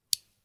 case-click.mp3